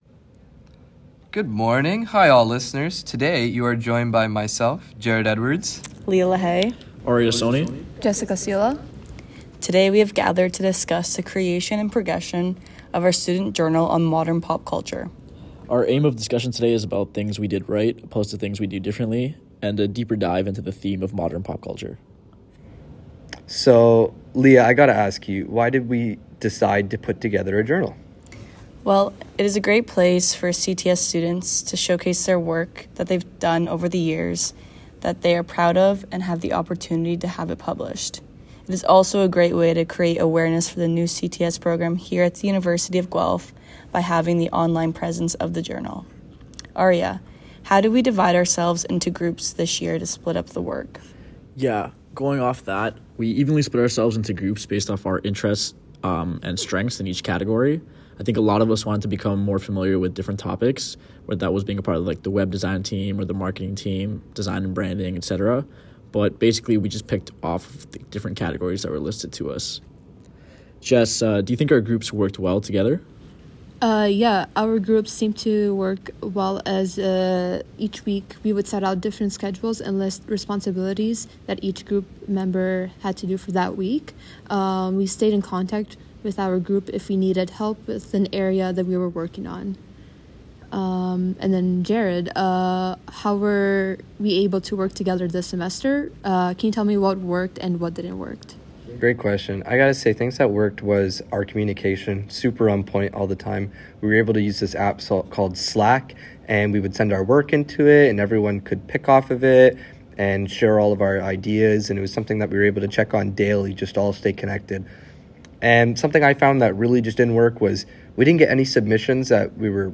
I was able to take all of our individual parts and edit out the background feedback on Garageband (any music software would work).